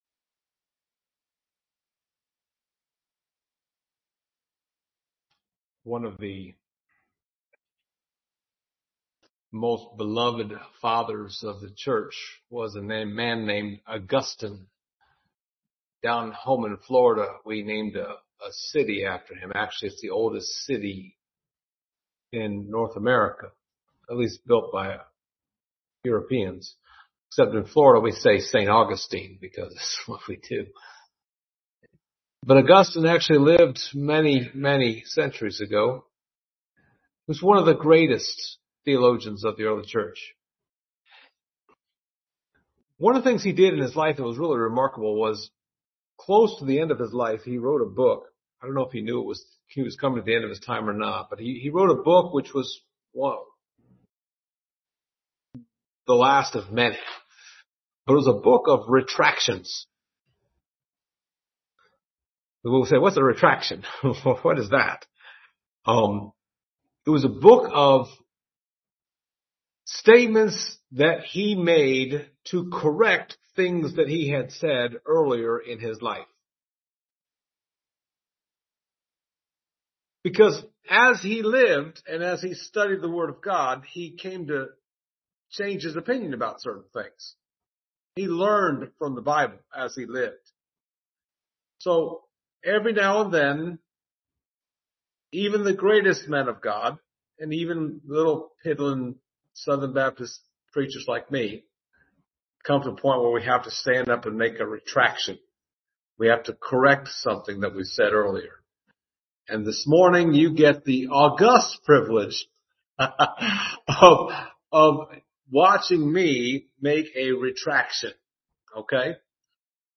Service Type: Sunday Morning Topics: prophecy , spiritual gifts